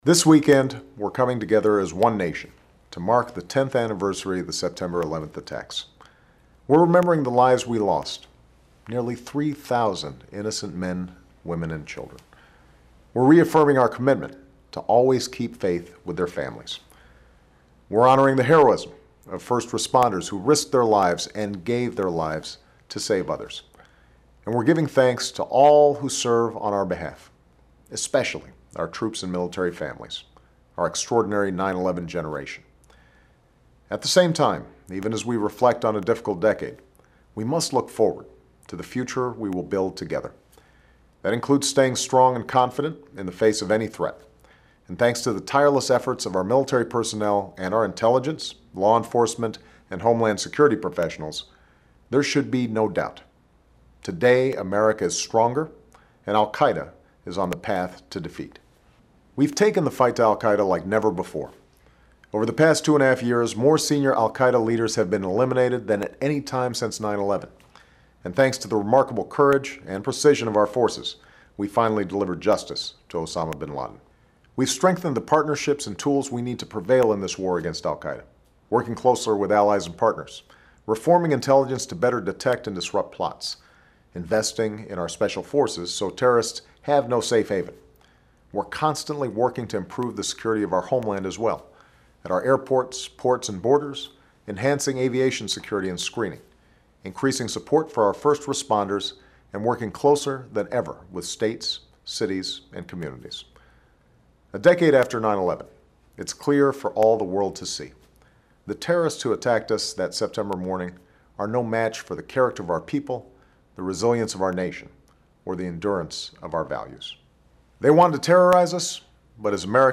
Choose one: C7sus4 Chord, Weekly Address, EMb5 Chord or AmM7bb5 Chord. Weekly Address